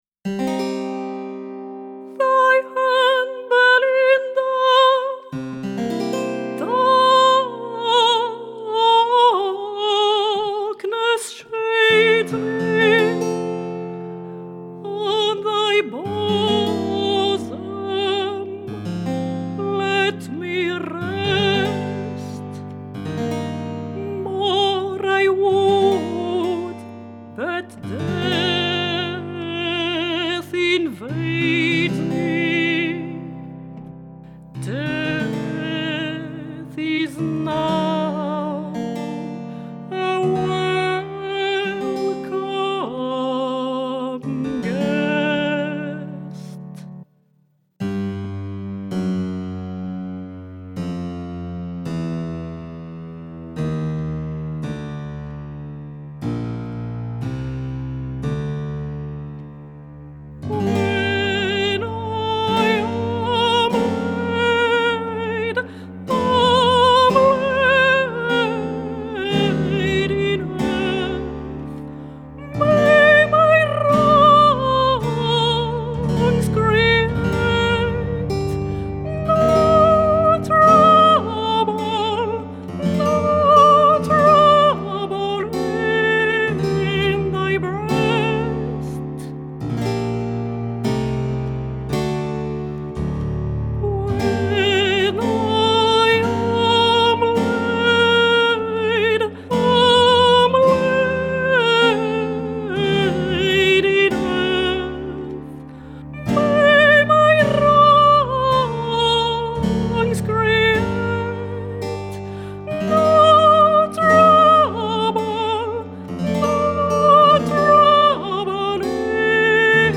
- Mezzo-soprano Soprano